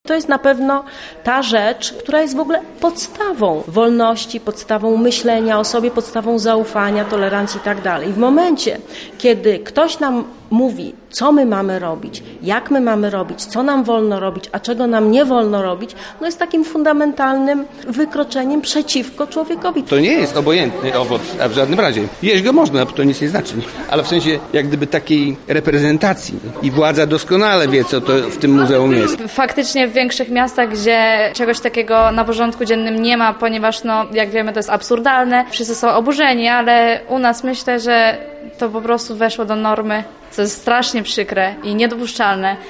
Lublinianie w strugach deszczu zaprotestowali przeciwko cenzurowaniu sztuki przez rząd.